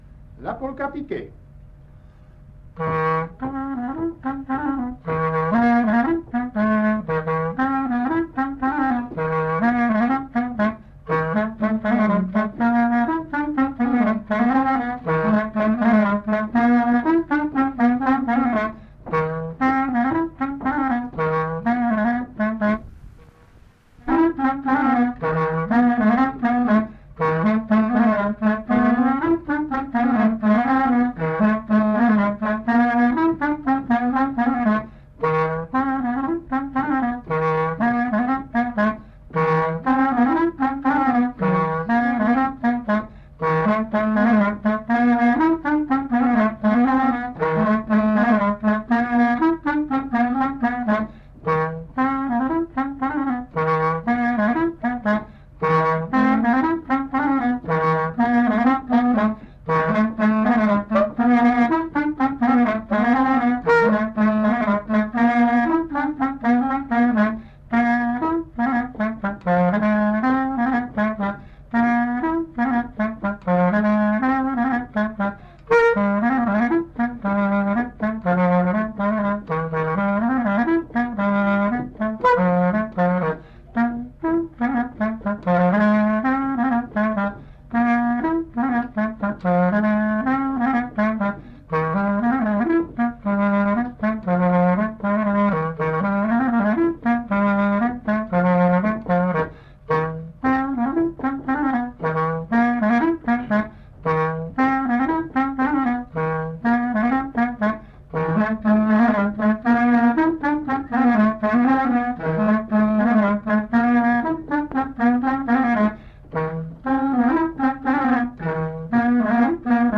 Aire culturelle : Couserans
Genre : morceau instrumental
Instrument de musique : clarinette
Danse : polka piquée